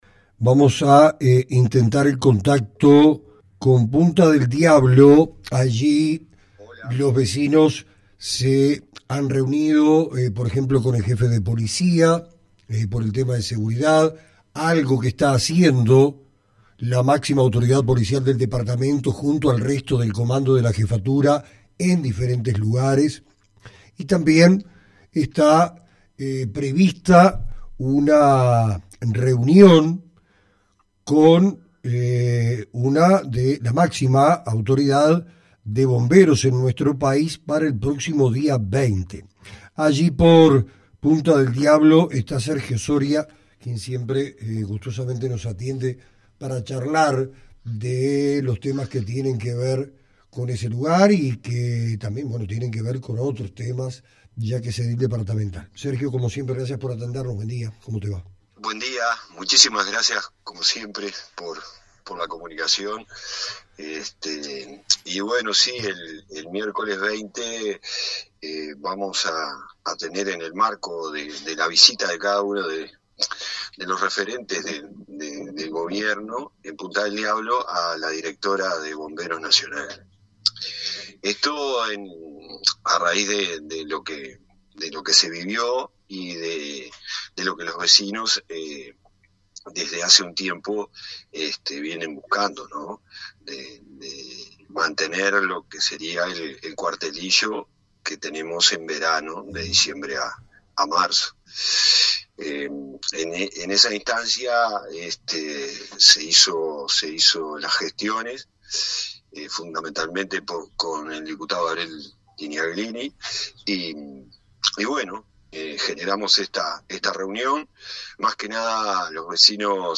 En una reciente entrevista con Sergio Soria, edil departamental y residente del lugar, se detalló la importancia de dos reuniones clave programadas para los próximos días.